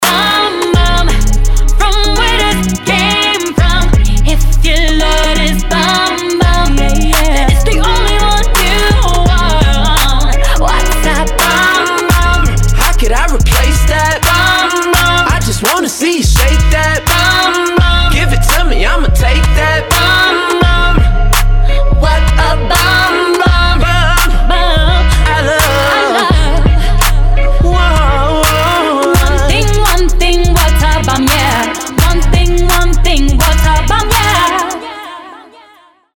• Качество: 320, Stereo
Хип-хоп
RnB